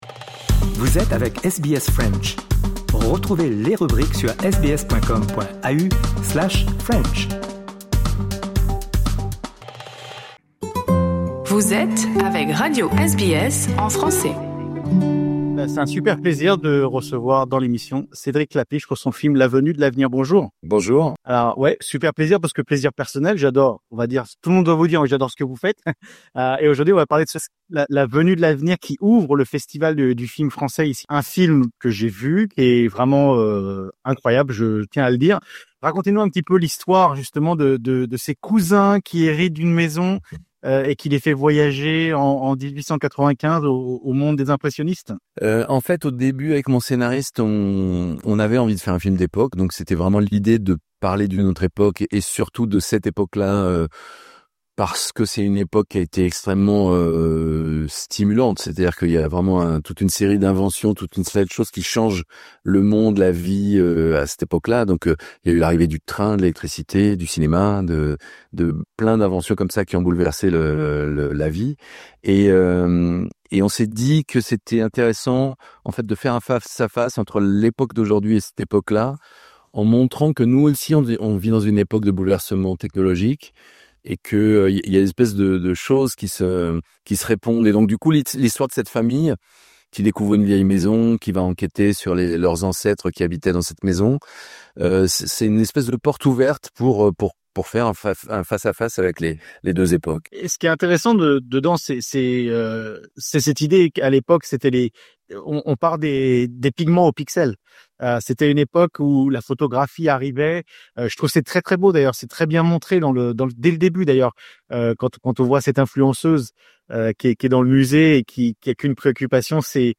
Invité de notre émission, Cédric Klapisch revient sur La Venue de l’Avenir, son nouveau film qui ouvre le Festival du film français de l'Alliance Francaise en 2026.